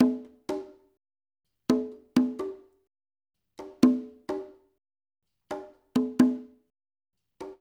LAY CONGAS-L.wav